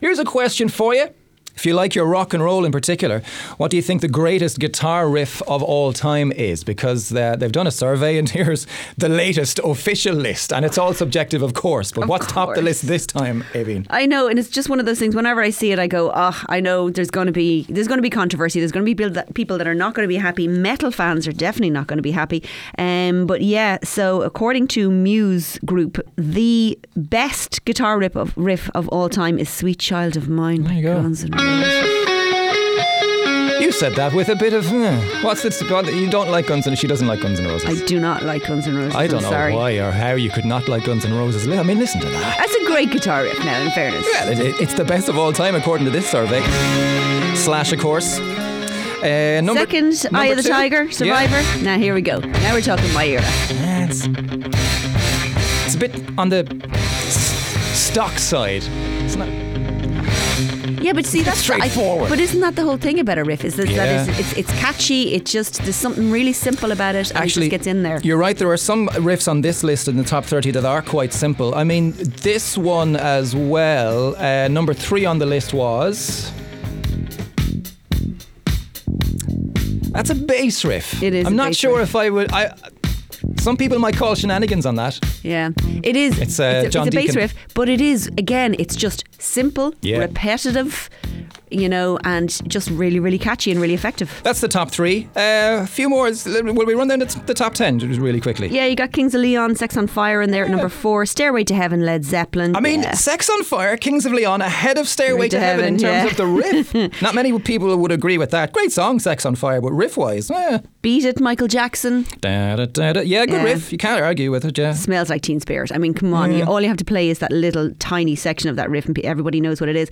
The Big Breakfast Blaa is back tomorrow from 6am on WLR!
Guitar-Riffs-1.wav